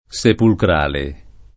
sepulcrale [ S epulkr # le ]